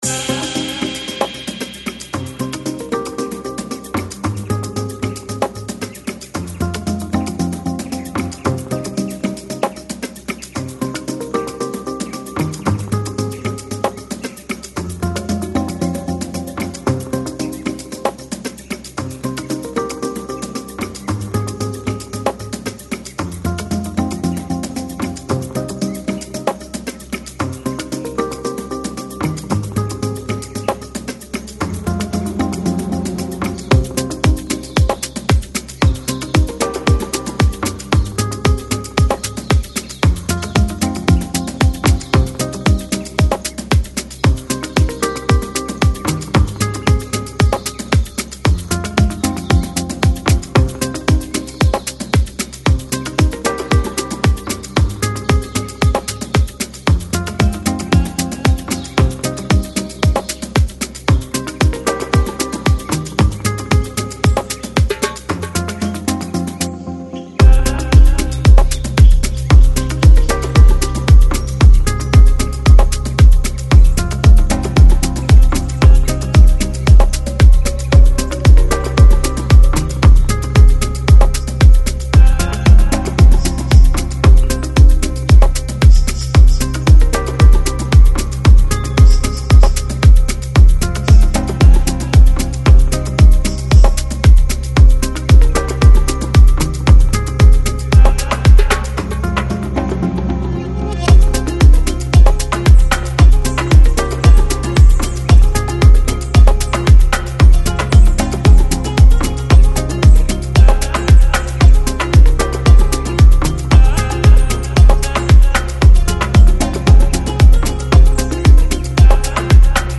Жанр: World, Ethnic, Oriental Folk